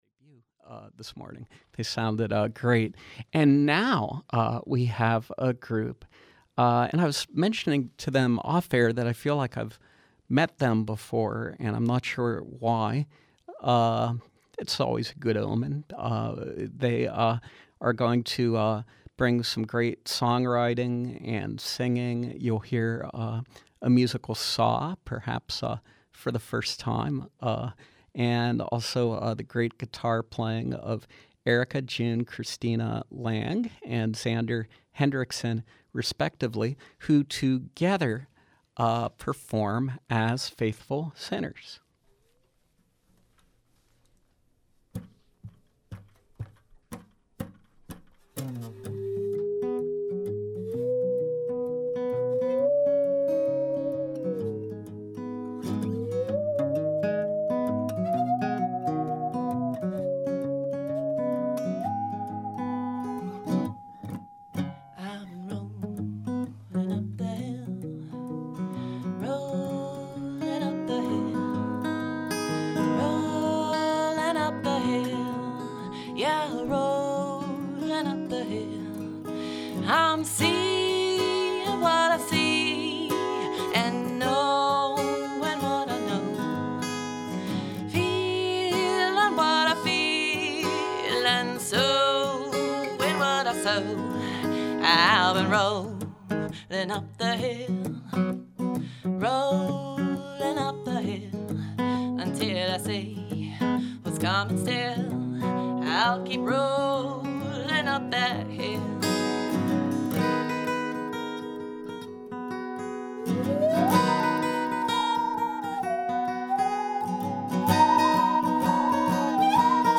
who also plays the musical saw
who plays acoustic guitar.
duo